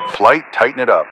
Added .ogg files for new radio messages
Radio-playerWingmanRejoin4.ogg